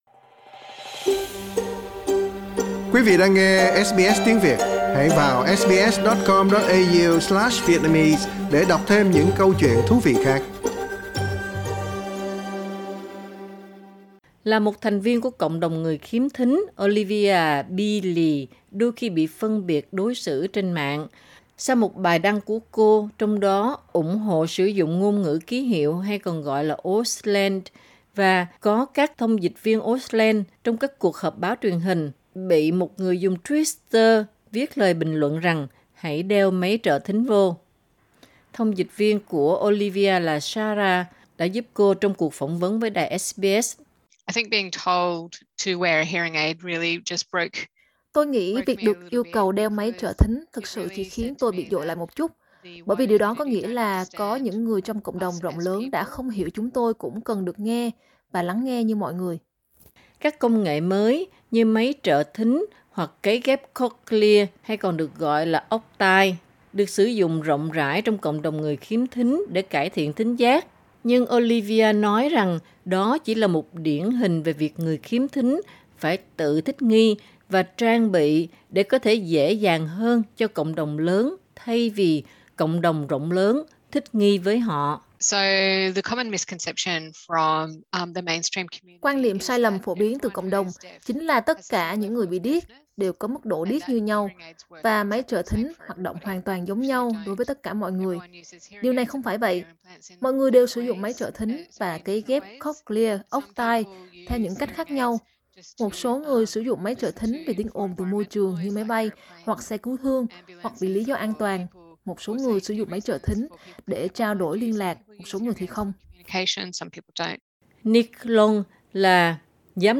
SBS Việt ngữ